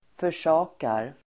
Ladda ner uttalet
Uttal: [för_s'a:kar]